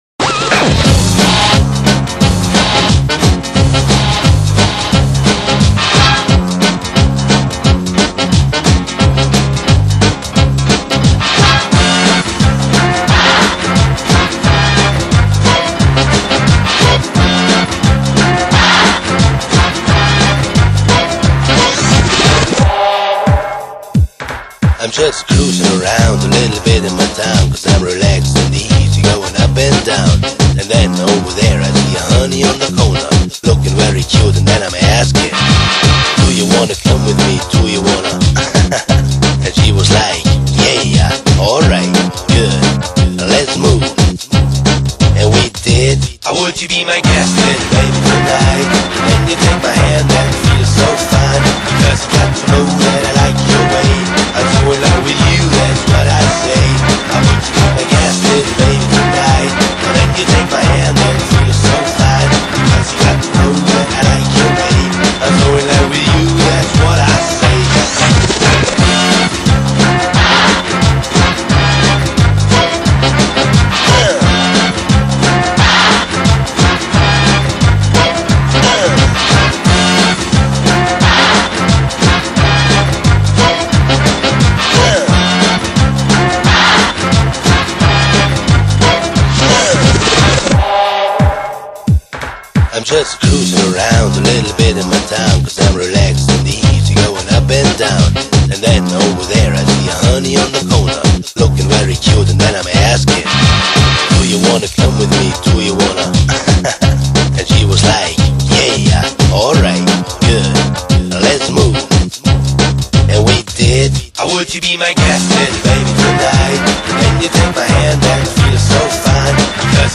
录DANCE），是一张具有曼波神韵的音乐专辑。
门金曲，旋律性强而富有动感，散发着无穷的时尚气息。